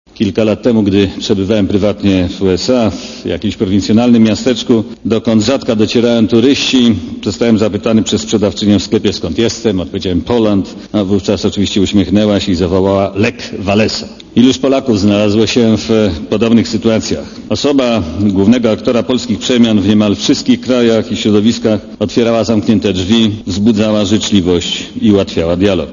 Powszechne uznanie dla niego na świecie przyczyniło się do wzrostu międzynarodowej pozycji Polski - Wałęsę chwalił szef polskiej dyplomacji, Włodzimierz Cimoszewicz.
Komentarz audio